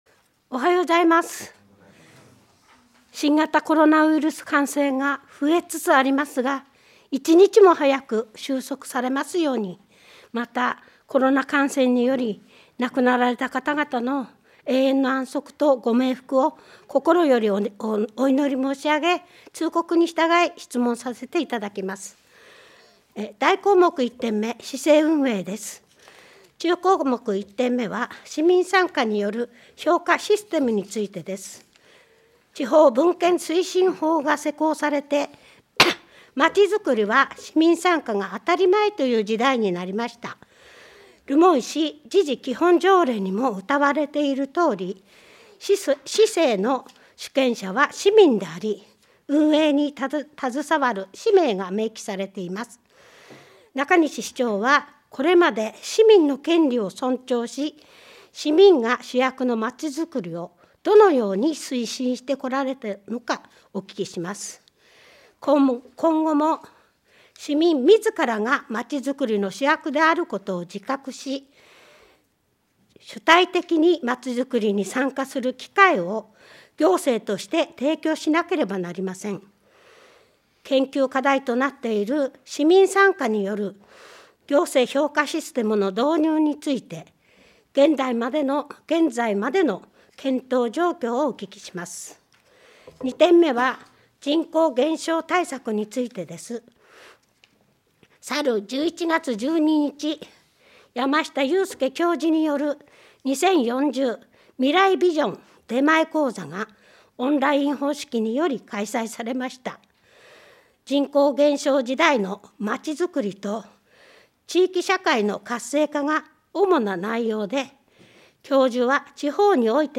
議会録音音声